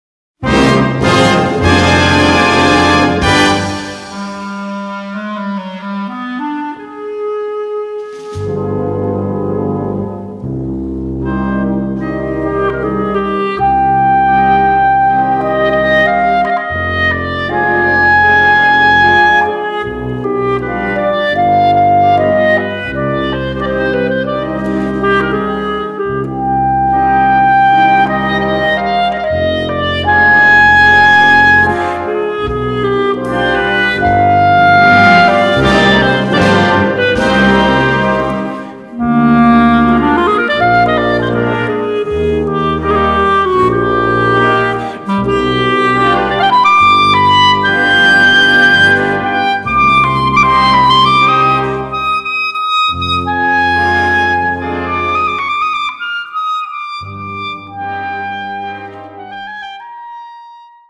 Besetzung: Instrumentalnoten für Klarinette